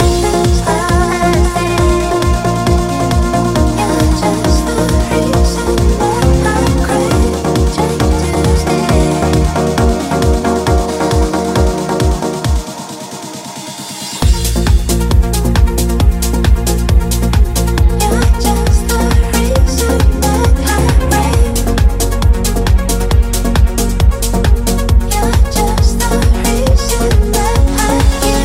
Genere: house,chill,deep,remix,hit